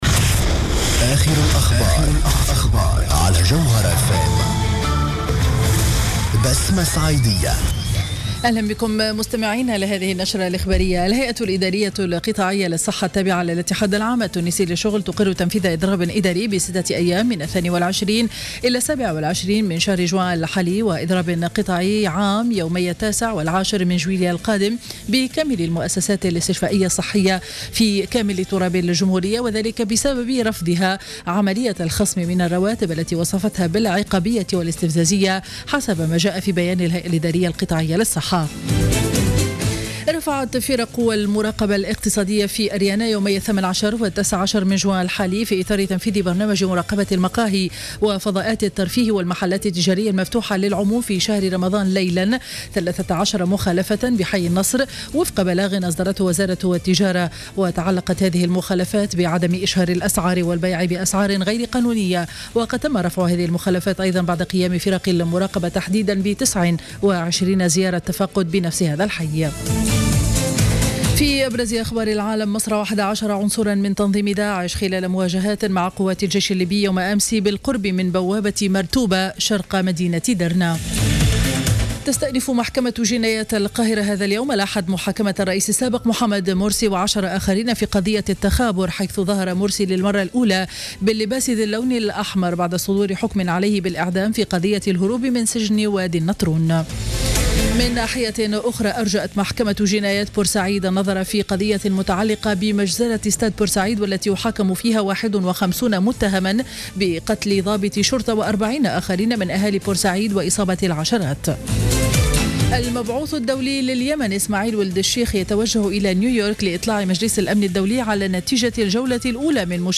نشرة أخبار منتصف النهار ليوم الأحد 21 جوان 2015